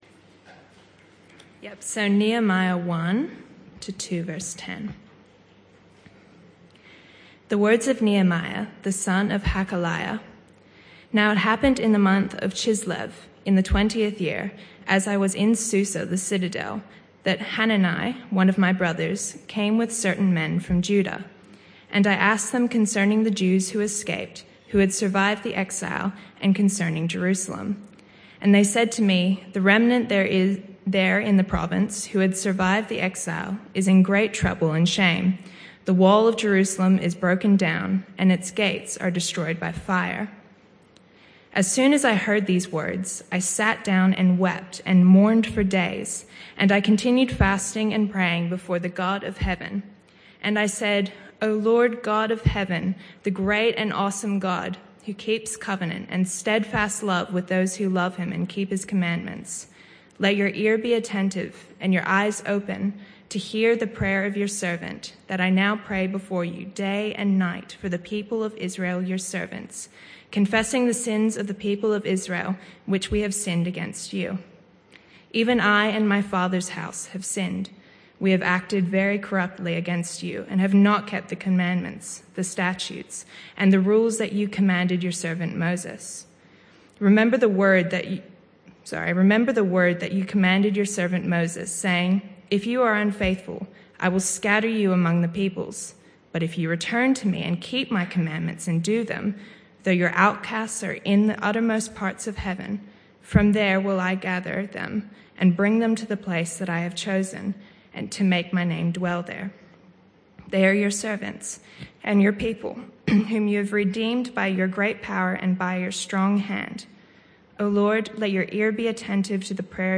This was a one-off talk.
Nehemiah 4 Service Type: Evening Service Bible Text